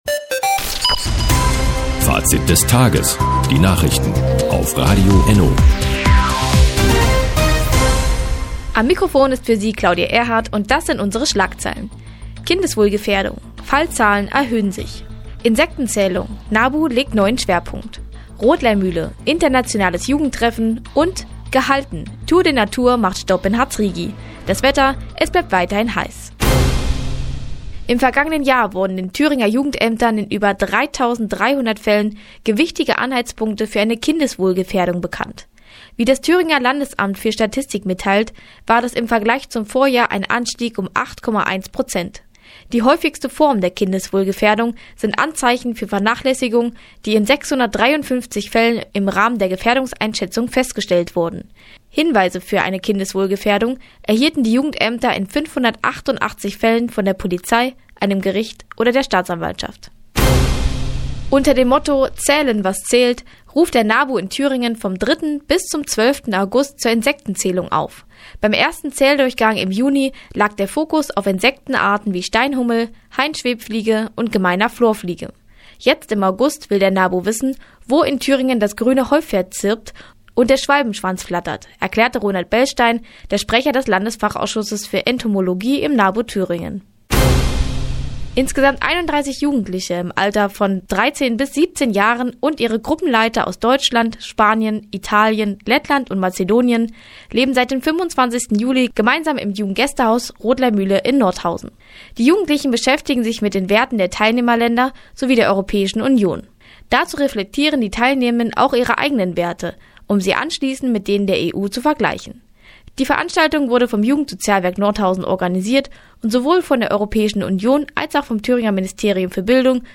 30.07.2018, 17:00 Uhr : Seit Jahren kooperieren die Nordthüringer Online-Zeitung und das Nordhäuder Bürgerradio ENNO: Die tägliche Nachrichtensendung ist jetzt hier zu hören...